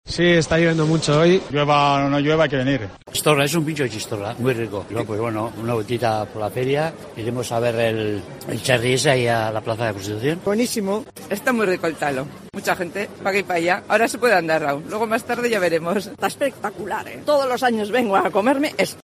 Ambiente inmejorable, a pesar de la lluvia, en la Feria de Santo Tomás de San Sebastián